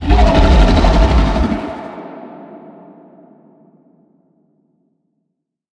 Sfx_creature_iceworm_vo_close_02.ogg